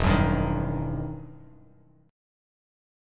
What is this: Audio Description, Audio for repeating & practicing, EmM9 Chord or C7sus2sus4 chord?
EmM9 Chord